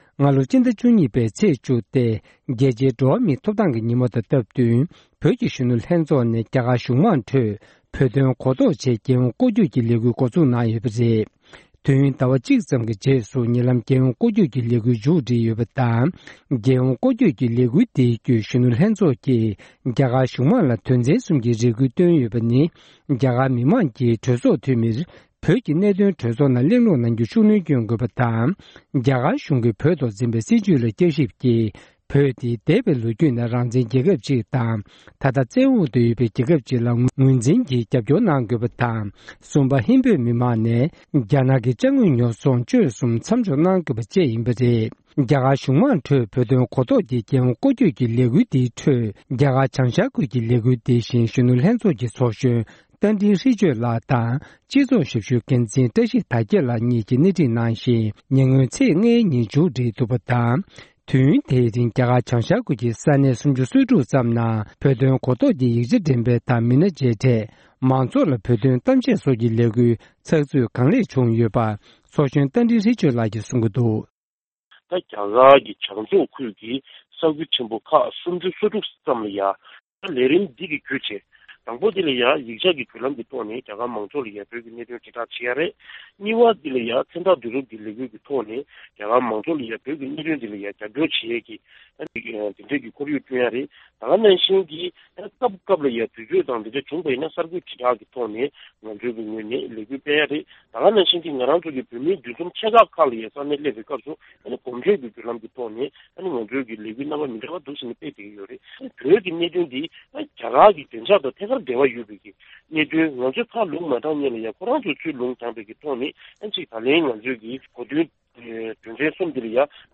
བོད་ཀྱི་གཞོན་ནུ་ལྷན་ཚོགས་ནས་རྒྱ་གར་གཞུང་དམངས་འཁྲོད་བོད་དོན་གོ་རྟོགས་ཀྱི་གཞི་རྒྱ་ཆེ་བའི་རྒྱལ་ཡོངས་སྐོར་བསྐྱོད་ལེགས་གྲུབ་ཇི་བྱུང་སྐོར་བཅར་འདྲི།